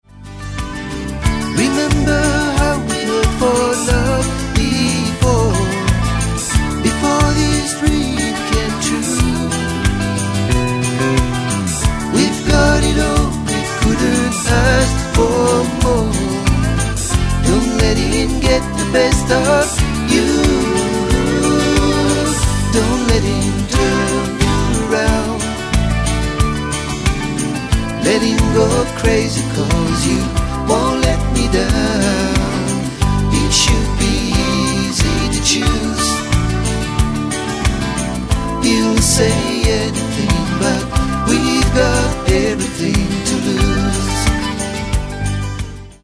Basse, Chant, Harmonies
Banjo
Batterie
Guitare Electrique